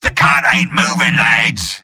Robot-filtered lines from MvM.
{{AudioTF2}} Category:Sniper Robot audio responses You cannot overwrite this file.